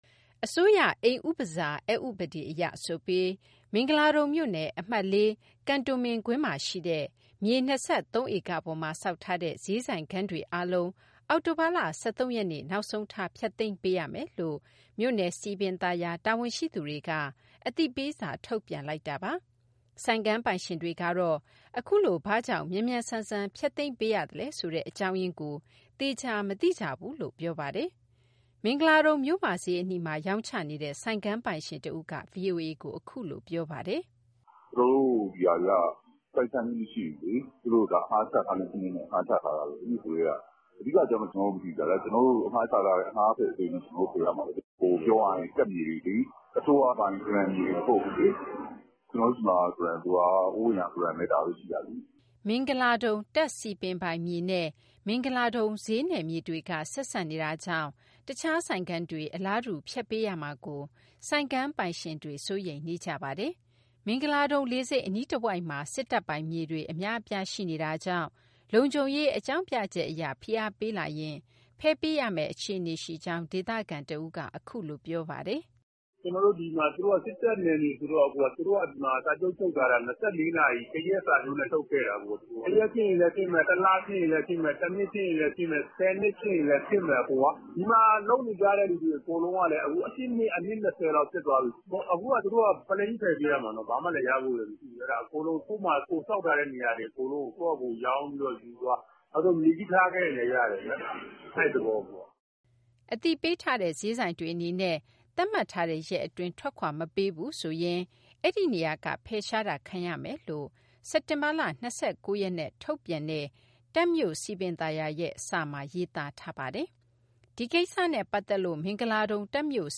အစိုးရအိမ်ဥပစာ (နှင်ထုတ်ခြင်း) အက်ဥပဒေအရ ဆိုပြီး မင်္ဂလာဒုံမြို့နယ် အမှတ်(၄) ကန်တိုမင်ကွင်းမှာရှိတဲ့ မြေ ၂၃ ဧကပေါ်မှာဆောက်ထားတဲ့ ဈေးဆိုင်ခန်းတွေအားလုံး အောက်တိုဘာလ ၁၃ ရက်နေ့ နောက်ဆုံးထား ဖျက်သိမ်းပေးရမယ်လို့ မြို့နယ်စည်ပင်သာယာ တာဝန်ဝန်ရှိသူတွေက အသိပေးစာ ထုတ်ပြန်လိုက်တာပါ။ ဆိုင်ခန်းပိုင်ရှင်တွေကတော့ အခုလို ဘာကြောင့် မြန်မြန်ဆန်ဆန် ဖျက်သိမ်းပေးရသလဲဆိုတဲ့ အကြောင်းရင်းကို သေချာမသိကြဘူးလို့ ပြောပါတယ်။ မင်္ဂလာဒုံမြို့မဈေးအနီးမှာ ရောင်းချနေတဲ့ ဆိုင်ခန်းပိုင်ရှင်တဦးက VOA ကို အခုလိုပြောပါတယ်။
မင်္ဂလာဒုံ တပ်စည်ပင်ပိုင်မြေနဲ့ မင်္ဂလာဒုံဈေးနယ်မြေတွေက ဆက်စပ်နေတာကြောင့် တခြားဆိုင်ခန်းတွေ အလားတူ ဖျက်ပေးရမှာကို ဆိုင်ခန်းပိုင်ရှင်တွေ စိုးရိမ်နေကြပါတယ်။ မင်္ဂလာဒုံလေဆိပ်အနီးတဝိုက်မှာ စစ်တပ်ပိုင်မြေတွေ အများအပြား ရှိနေတာကြောင့် လုံခြုံရေးအကြောင်းပြချက်အရ ဖိအားပေးလာရင် ဖယ်ပေးရမယ့် အခြေအနေရှိကြောင်း ဒေသခံတဦးက အခုလိုပြောပါတယ်။